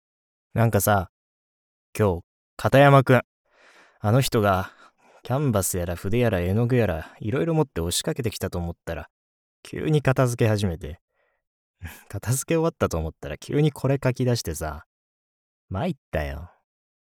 ボイスサンプル
セリフB